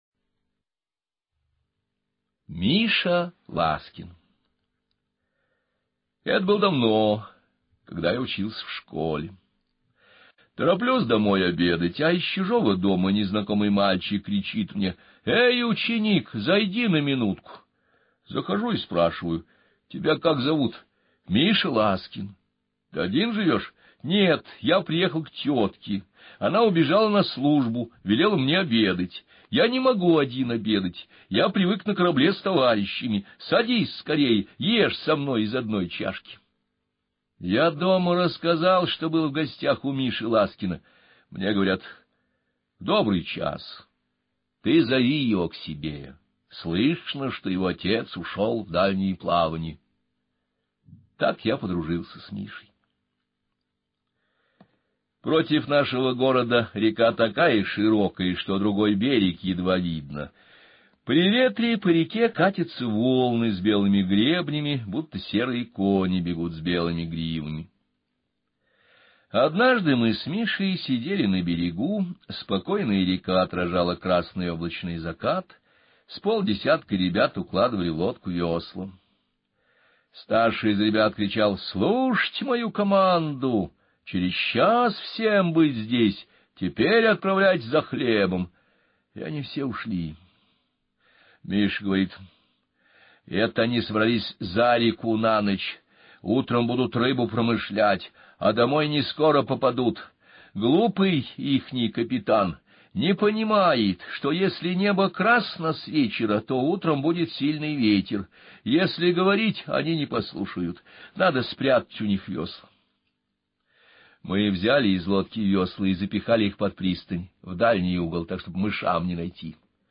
Миша Ласкин - аудио рассказ Шергина - слушать онлайн